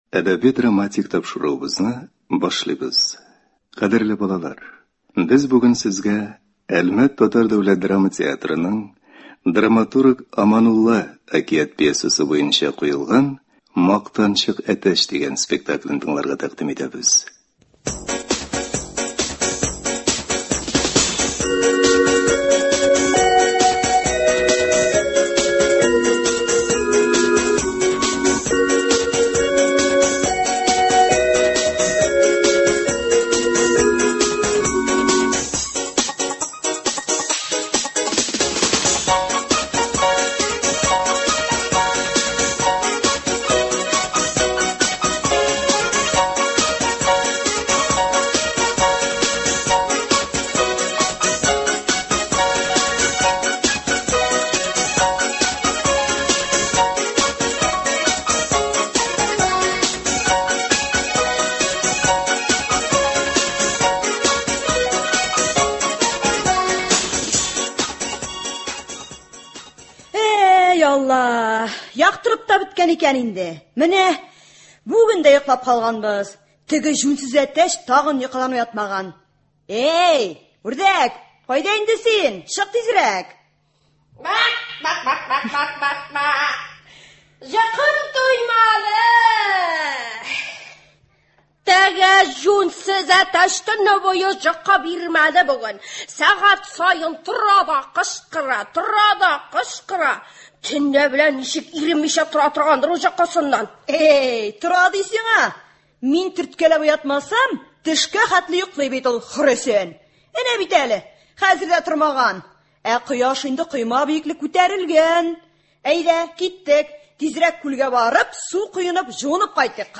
Аманулла. “Мактанчык әтәч”. Әлмәт ТДДТ спектакле.
Спектакль радио өчен 1998 елда яздырып алынган.